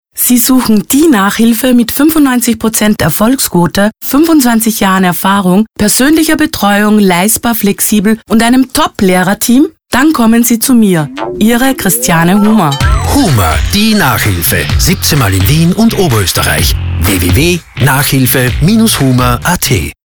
Unser Radiospot